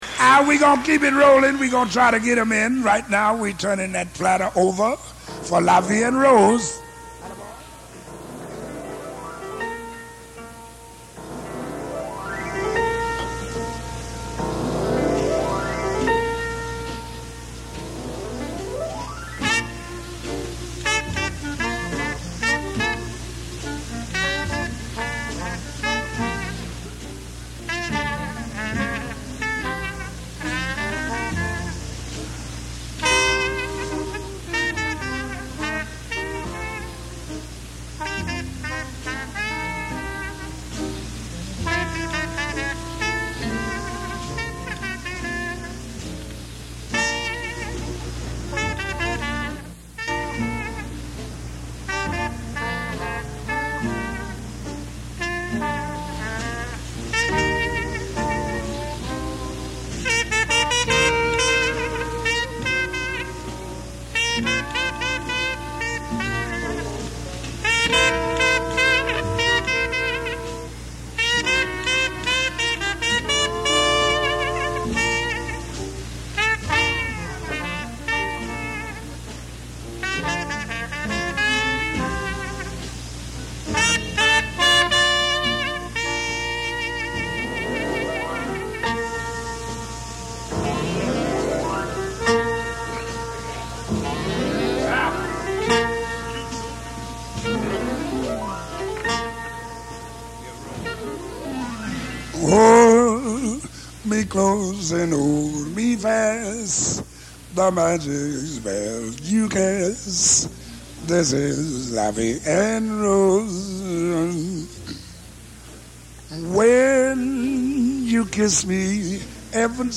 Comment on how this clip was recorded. Hinsdale High School, March 20, 1957